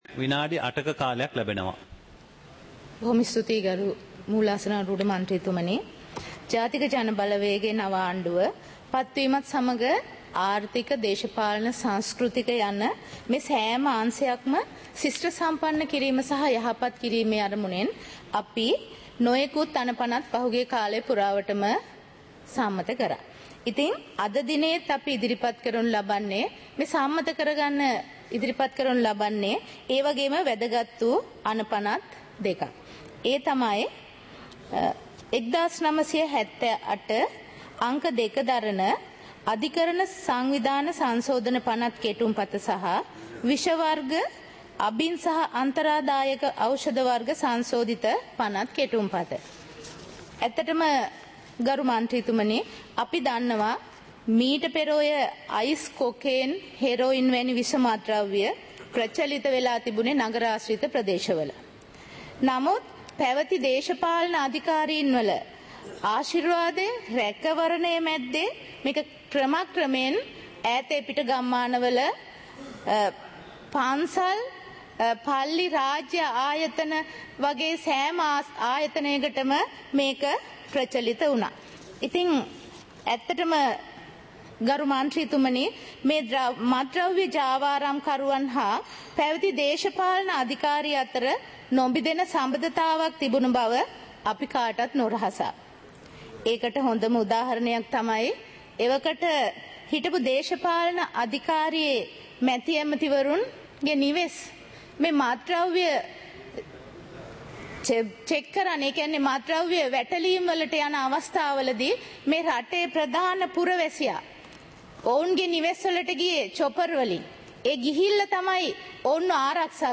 පාර්ලිමේන්තුව සජීවීව - පටිගත කළ